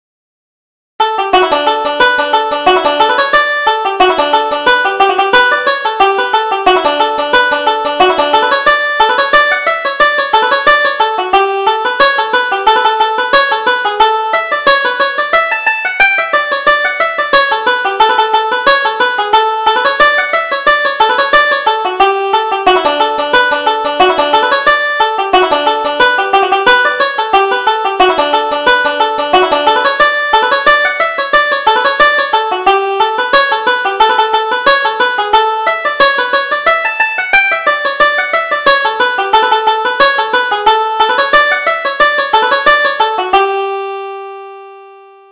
Reel: The Sligo Chorus